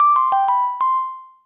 sms1.wav